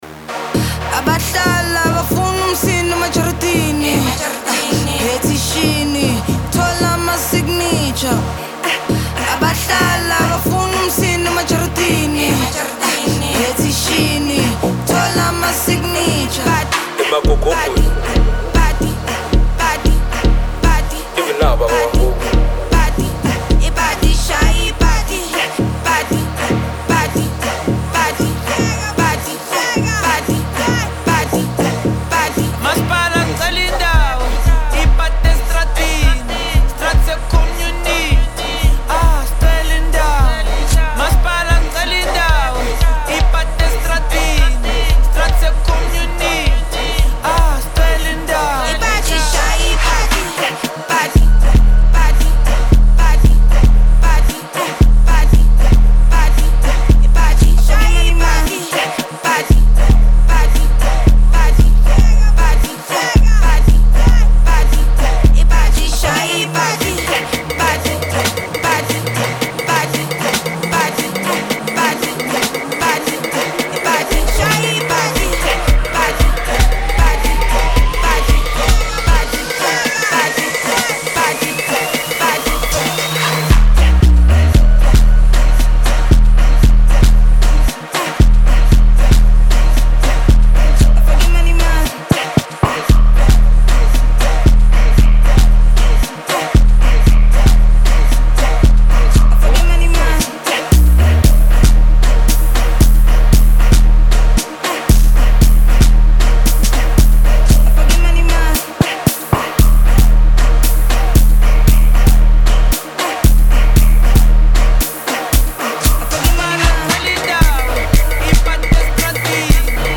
an amapiano songstress from the Southern part of Africa
well-produced amapiano track